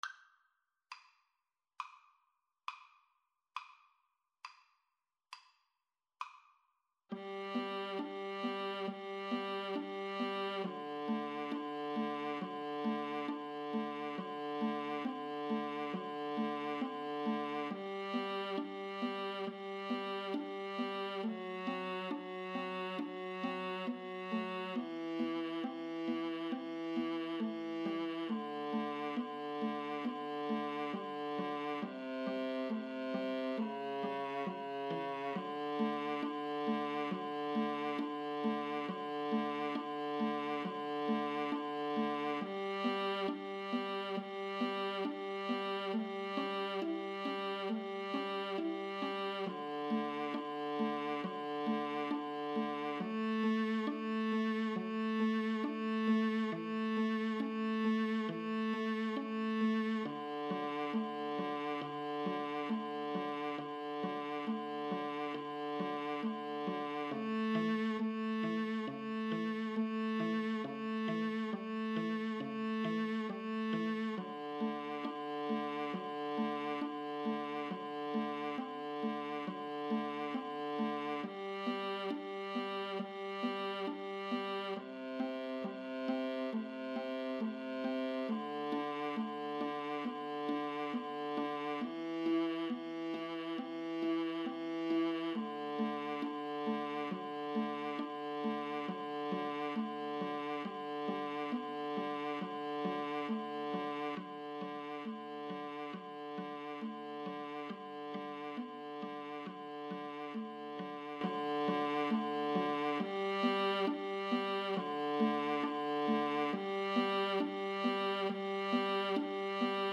= 34 Grave
4/4 (View more 4/4 Music)
Classical (View more Classical Viola Trio Music)